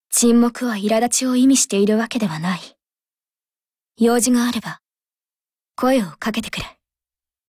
[[Category:碧蓝航线:邦克山语音]]